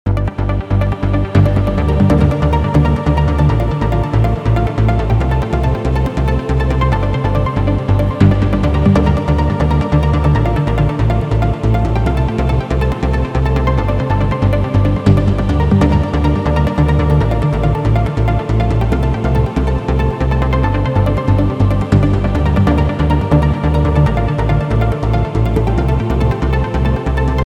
全体的にゲームBGM色溢れるものになっていますので、ゲーム好きの方ならきっと気に入ってくれるはずです。